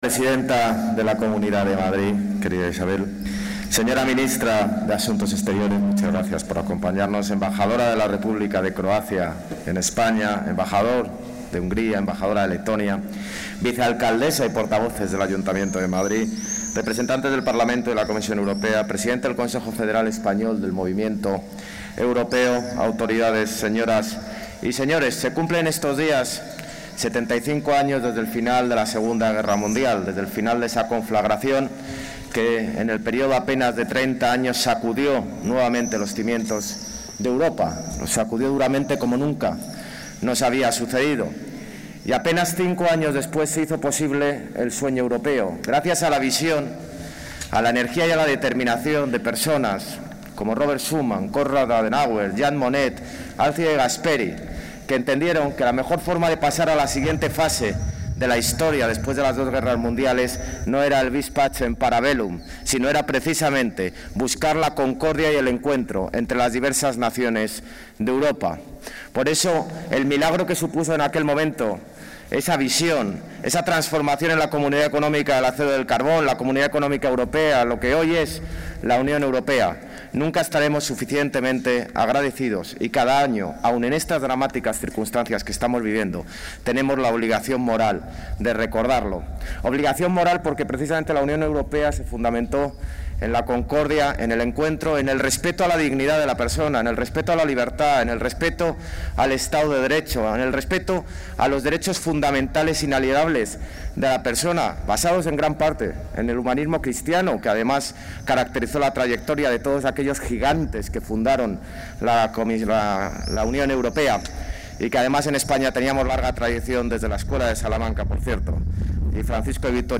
En su intervención en el acto de izado de la bandera de la UE en la plaza de la Villa con motivo de la conmemoración del Día de Europa
Nueva ventana:Intervención del alcalde de Madrid en el acto de conmemoración del Día de Europa